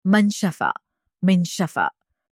towel-in-arabic.mp3